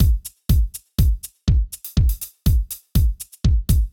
ORG Beat - Mix 4.wav